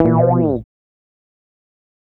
Bass Lick 35-01.wav